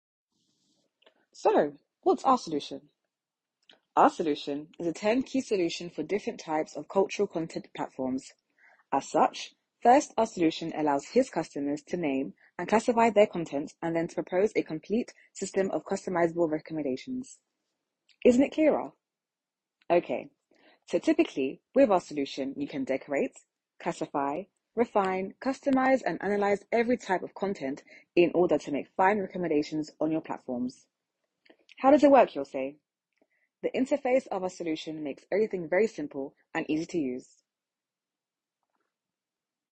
Bandes-son
English publicity
Voix off